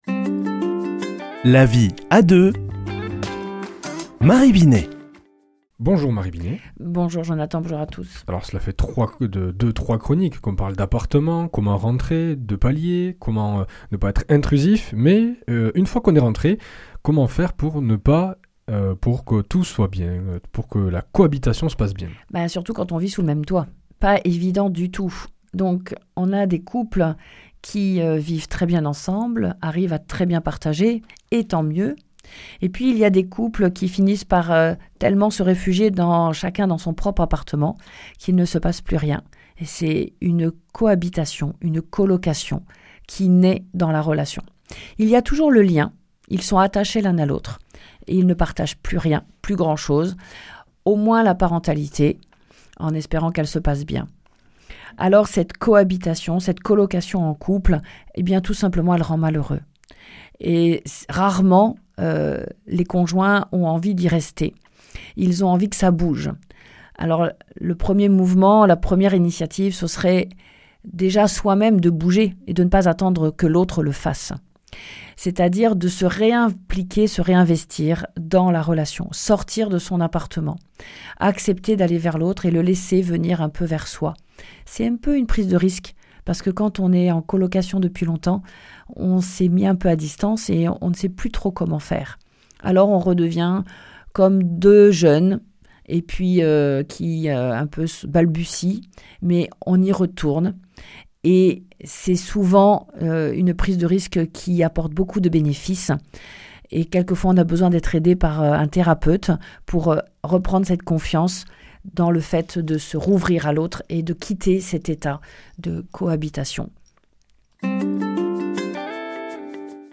mardi 27 janvier 2026 Chronique La vie à deux Durée 4 min
Une émission présentée par